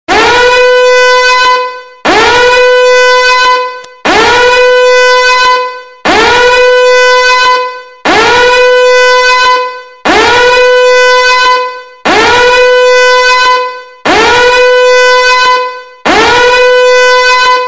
เสียง Alarm MP3 ฟรี
หมวดหมู่: เสียงเรียกเข้า
คำอธิบาย: ดาวน์โหลด เสียง Alarm mp3 ฟรี เป็นเสียงปลุกตอนเช้าสำหรับโทรศัพท์ของคุณ.../ Download Alarm clock Sounds mp3 free as morning alarm sound for your phone...